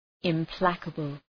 Προφορά
{ım’plækəbəl}
implacable.mp3